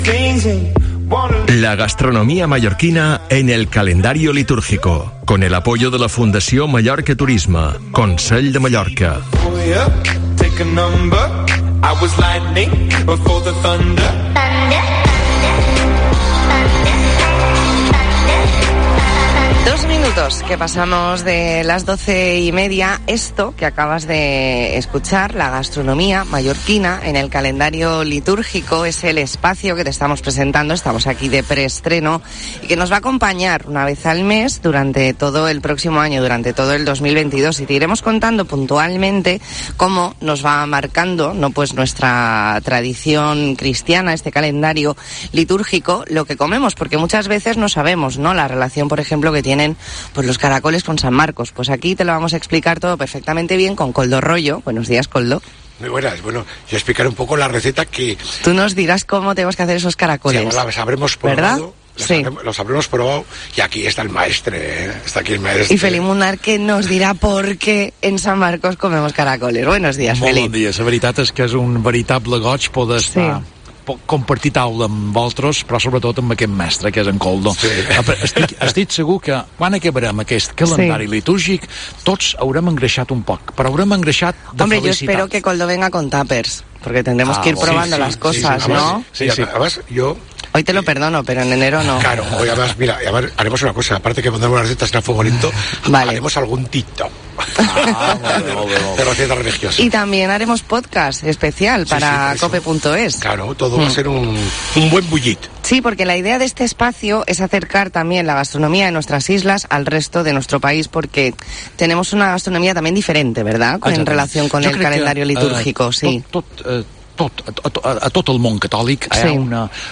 Entrevista en La Mañana en COPE Más Mallorca, jueves 16 de diciembre de 2021.